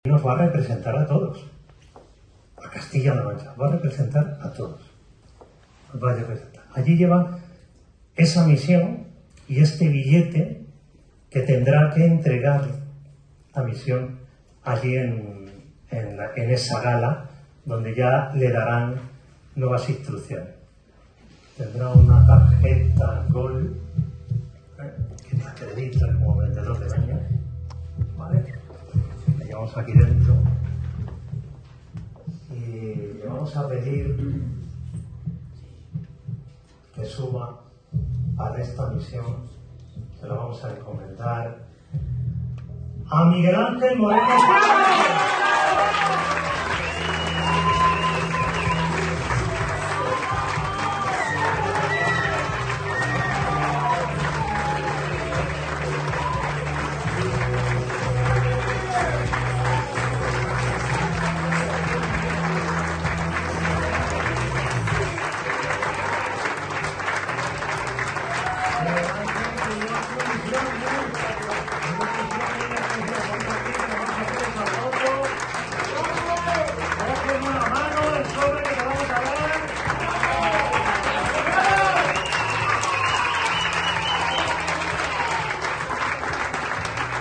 momento-en-el-que-se-desvela-el-vendedor-del-ano-de-castilla-la-mancha-mp3